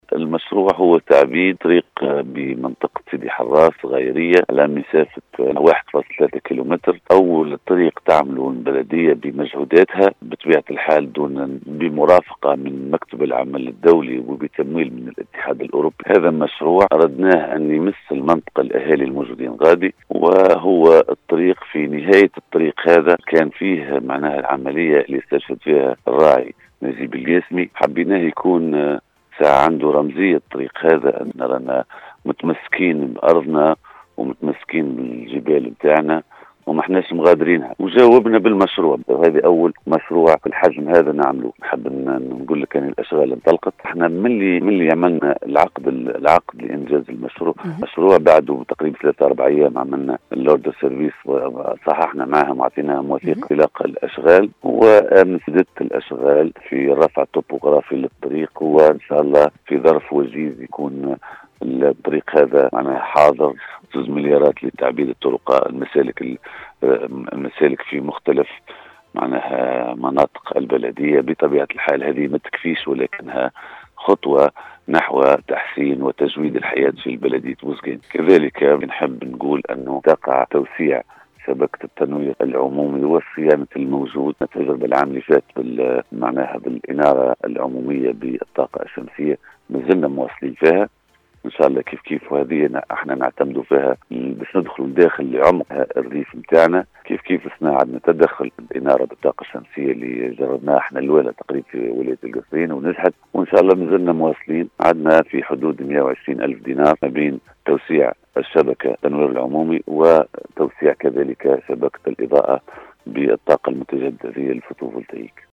أعلن رئيس بلديّة بوزقام ” فيصل الفالحي ” أثناء تدخّله  اليوم الأحد 14 مارس 2021 ببرنامج بونجور ويكاند بإذاعة السيليوم أ ف أم ، انطلاق مشروع تعبيد الطّريق بمنطقة الصغايريّة بسيدي حرّاث على مسافة 1.3كلم.  و هو أوّل طريق تنجزه البلديّة بمجهوداتها بمرافقة من مكتب العمل الدّولي بتمويل من الإتحاد الأوروبي .